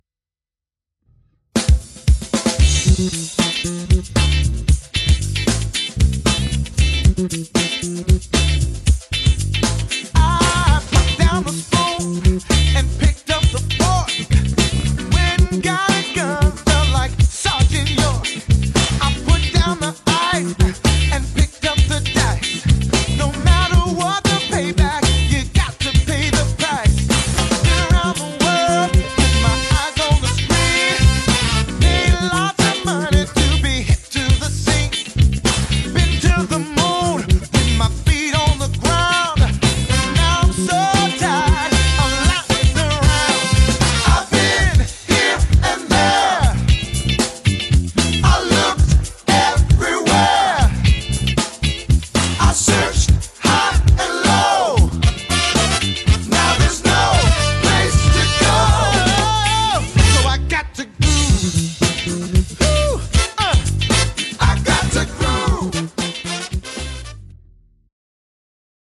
Jedná se Precision z dílny custom shopu v úpravě heavy relic.
Je víc konkrétní, má trochu více výšek, ale zároveň má pevný základ palisandru a jeho kulatost.
Zvukově naprosté dělo, ačkoliv díky hlazenkám není 100% univerzální, jak uslyšíte z nahrávek.